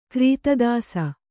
♪ krīta dāsa